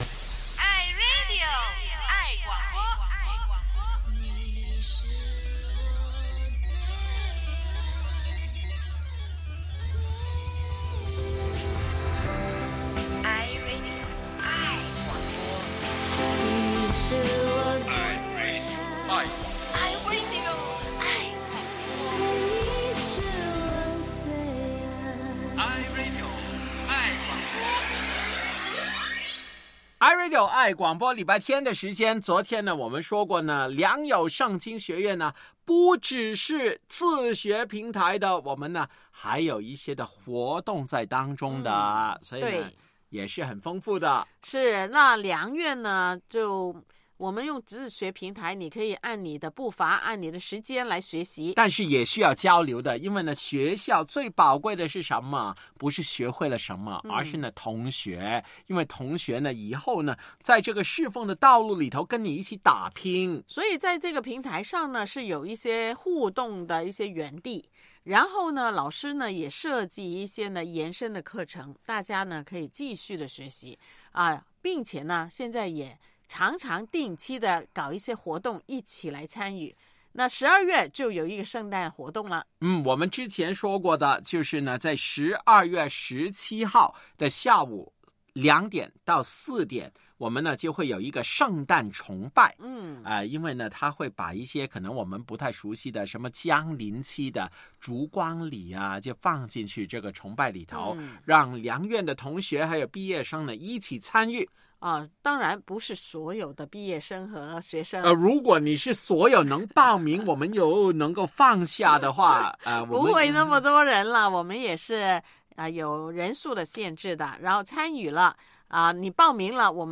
良友会客厅：集合婆婆、妈妈和女儿的一次访问，姊妹们是如何开始收听良友的呢？